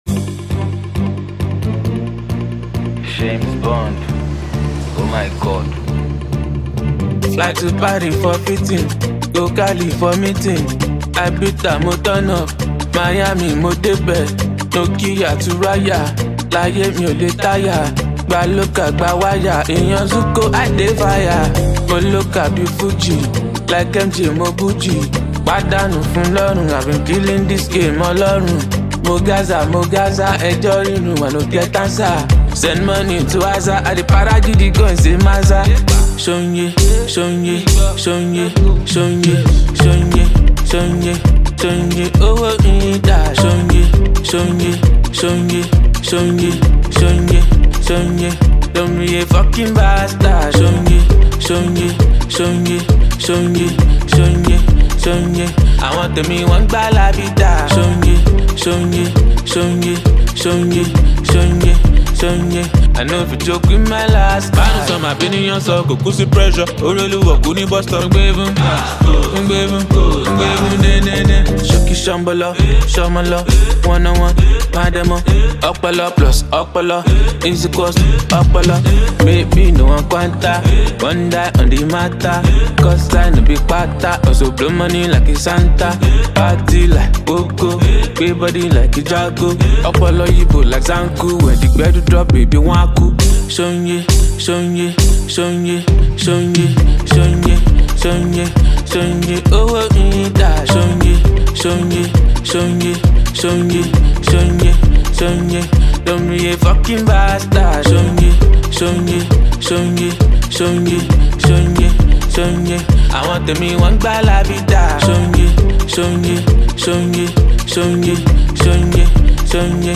dance banger